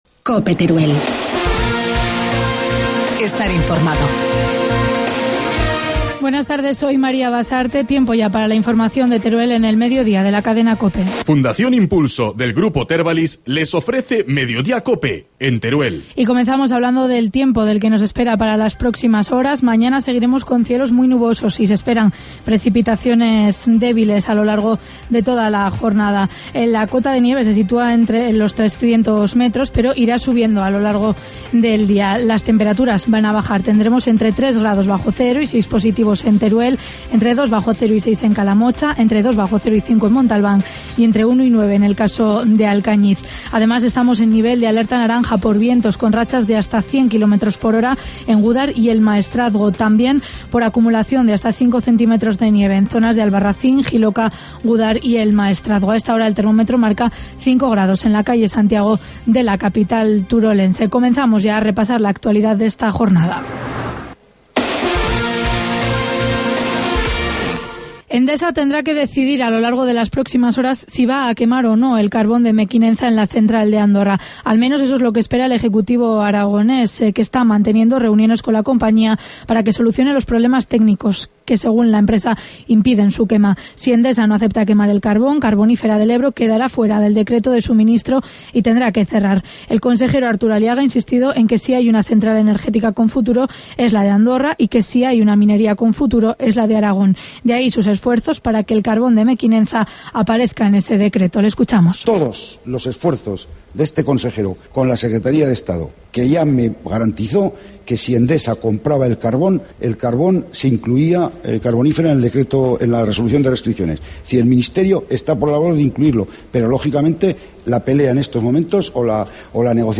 Informativo mediodía, miércoles 6 de febrero